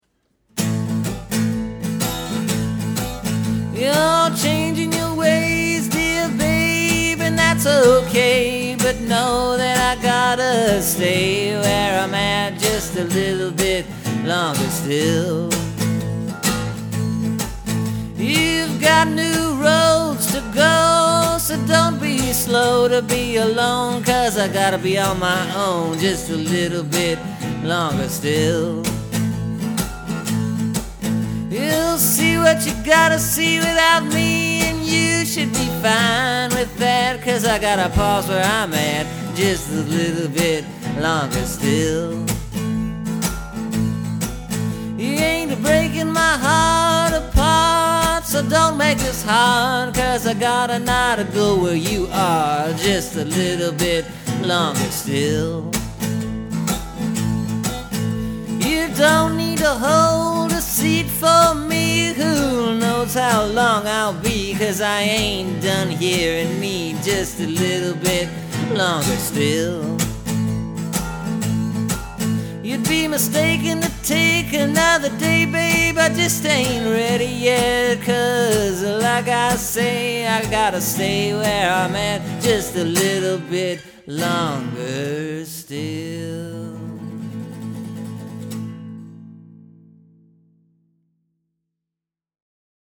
It’s kinda different, I think. I played bar-chords in this one, so it’s got a slightly different sound.
Bluesy with a repeating chorus-line at the end of each verse.